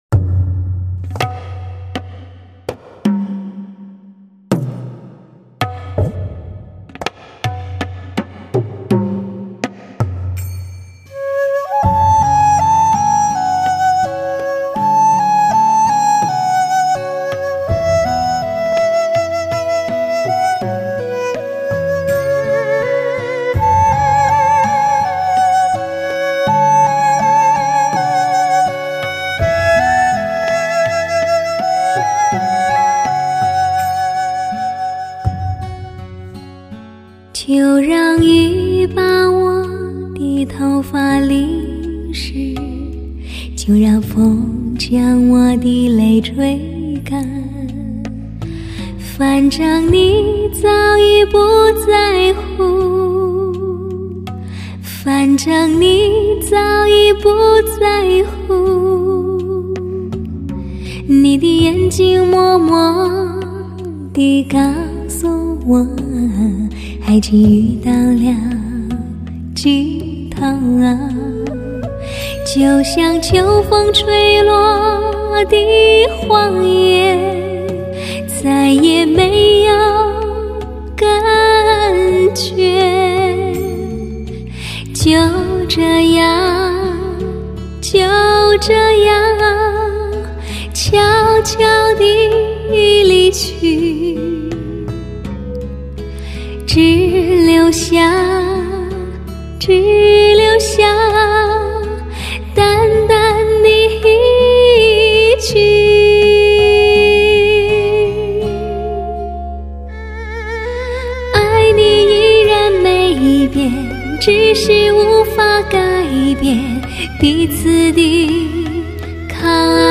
音乐类型: 流行音乐/Pop
深情代表作 首席疗伤音乐女声魅力